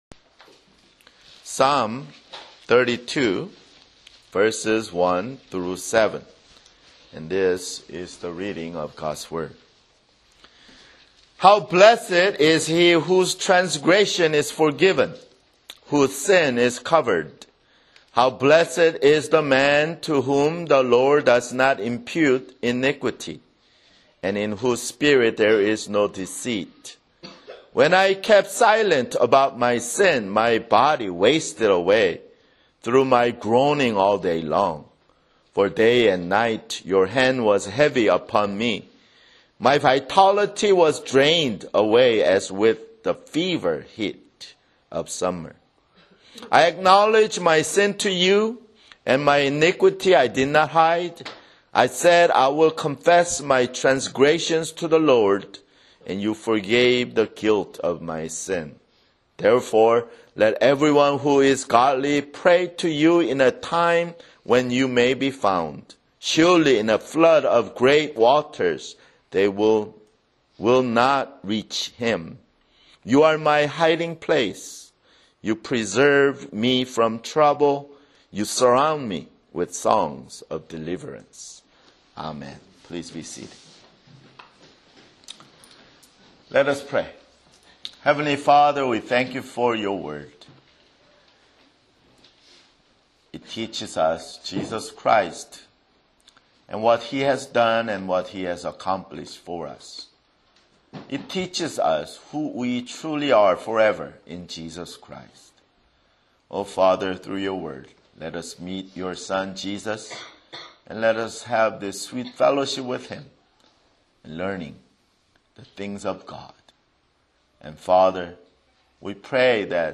[Sermon] Psalm 32:1-7
Psalm 32:1-7 (Lord's Supper)